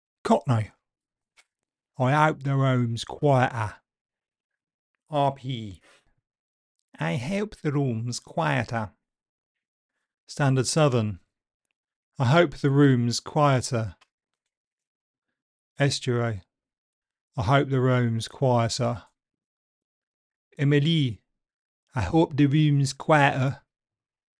5 London Accents - 1925 to 2025 - Pronunciation Studio
5-london-accents-compared.mp3